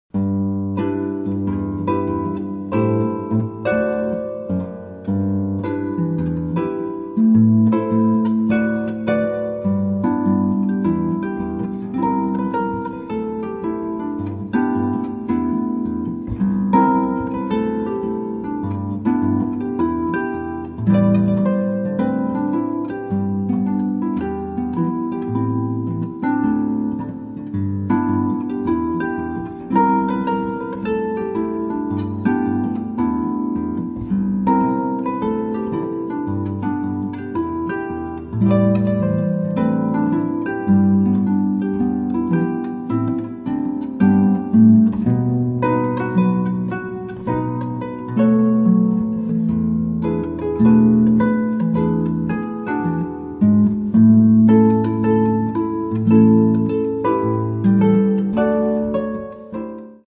Genre: Christmas